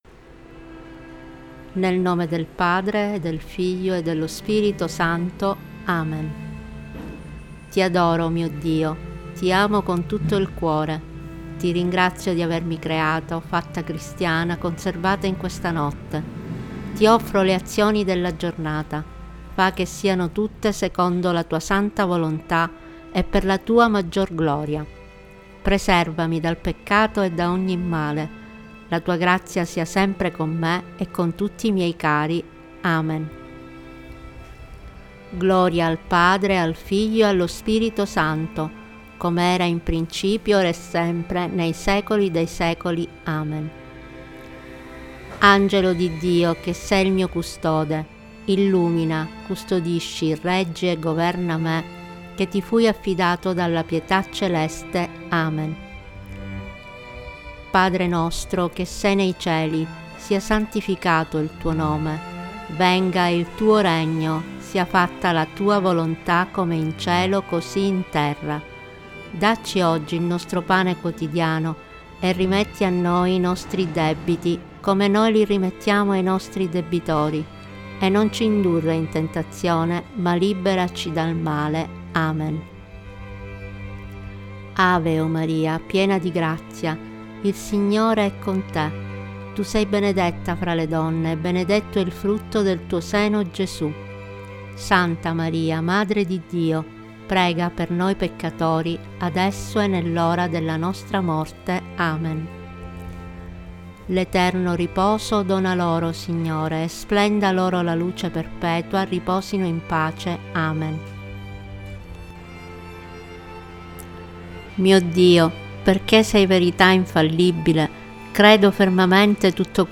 Preghiere-del-mattino-Tempo-di-preghiera-musica.mp3